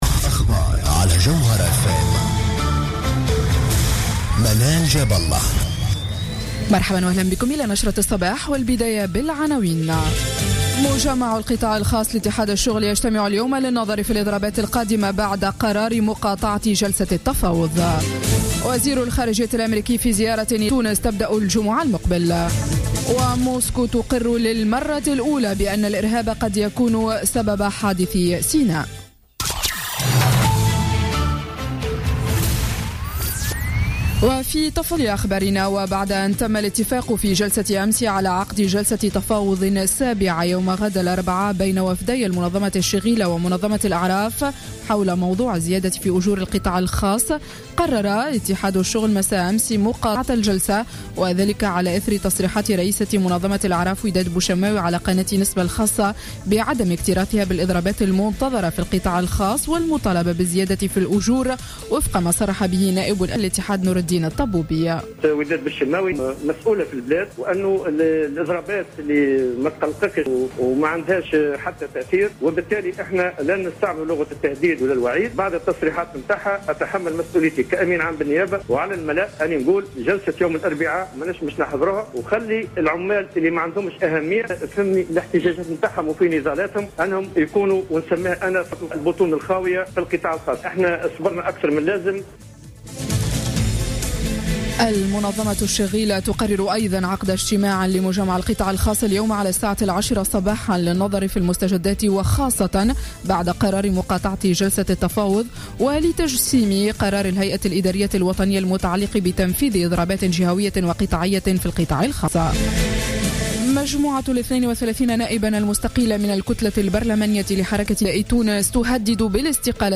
نشرة أخبار السابعة صباحا ليوم الثلاثاء 10 نوفمبر 2015